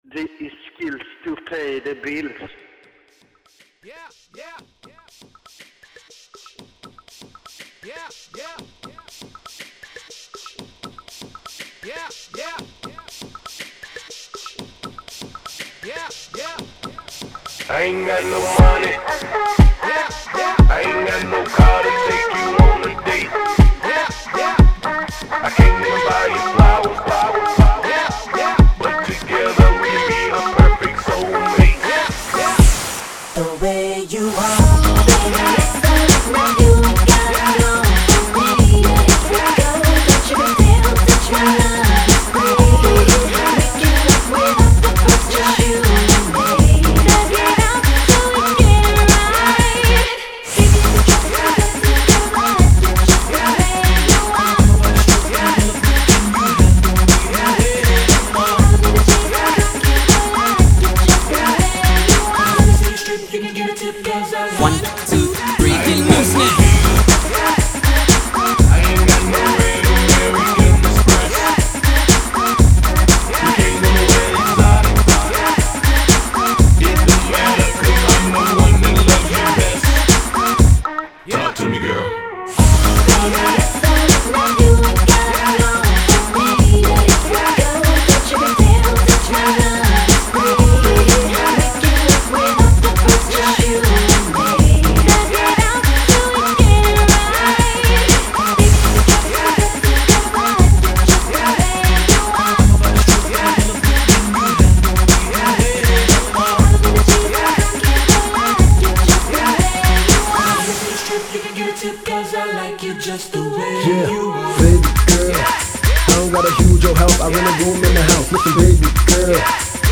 Genre: Hip-Hop